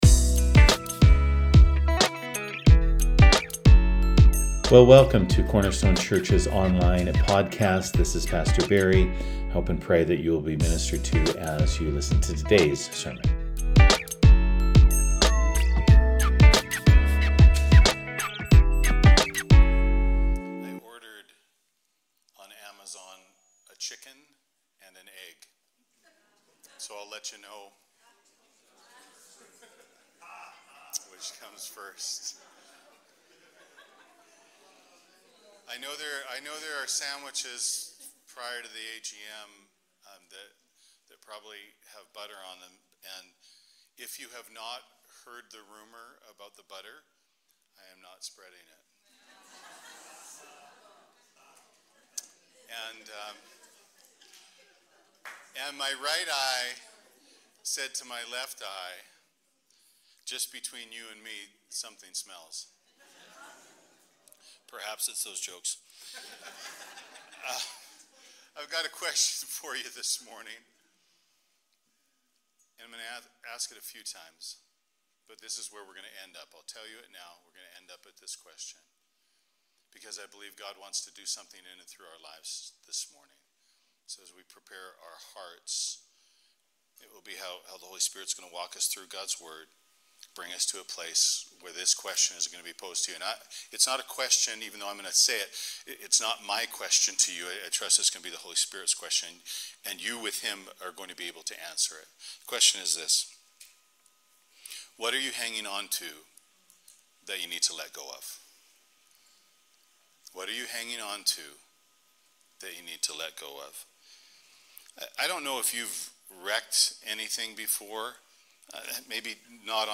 In today's sermon